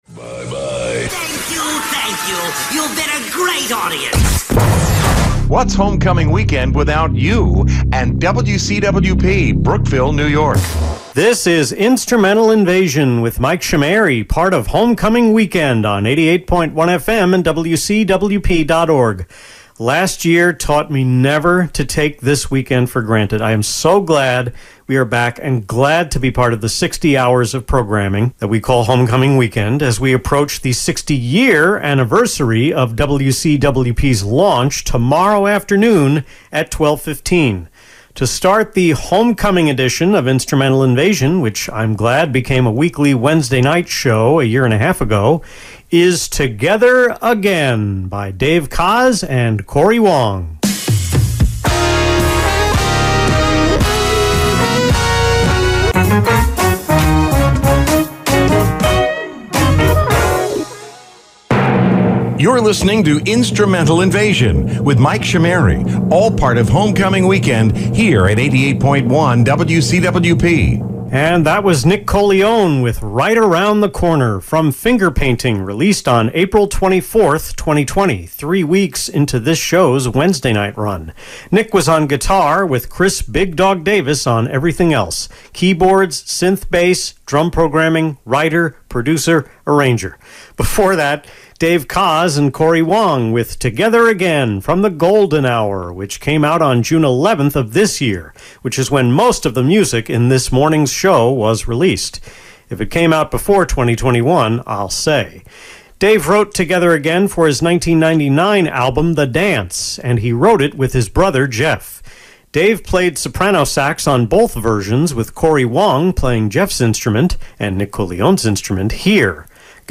The 2021 WCWP Homecoming Weekend edition of Instrumental Invasion was mainly recorded on September 26 with the last two talk breaks done on the 27th. Tweaks to the second hour were recorded in the early morning hours on the 28th during a bout of insomnia.